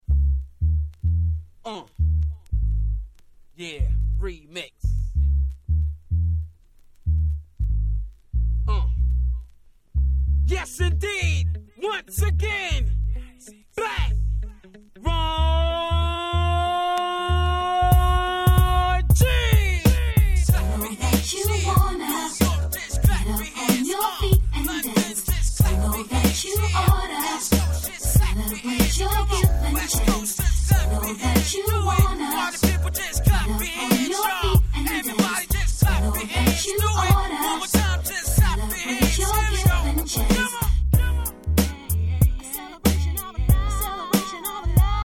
94' Nice UK R&B !!
音の方はUKのキャッチーな感じ7割、USのHip Hop Soulな雰囲気3割と言った感じでしょうか？